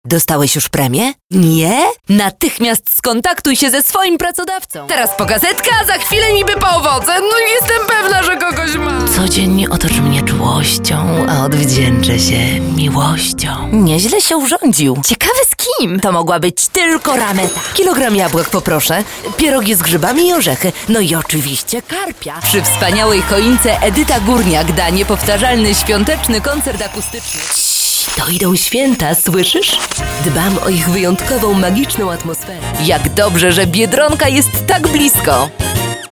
Sprecherin polnisch
Sprechprobe: Werbung (Muttersprache):
female voice over artist polish